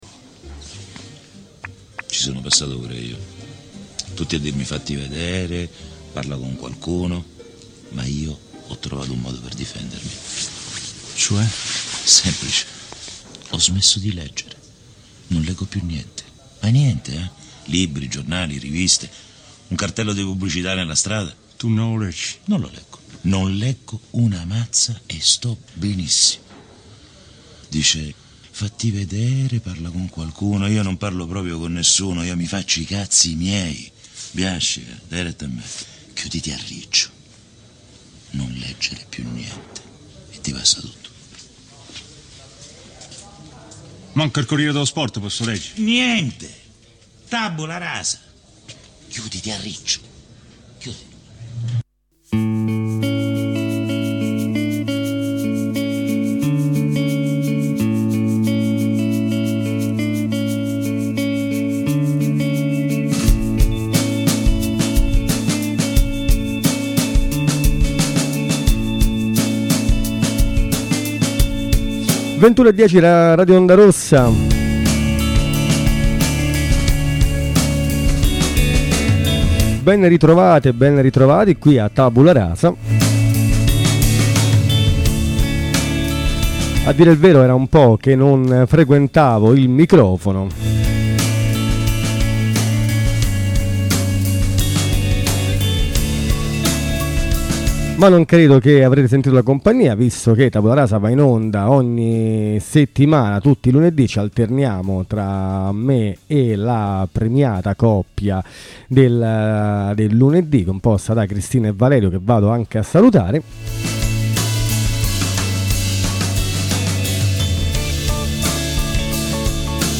La trasmissione che legge i libri per voi.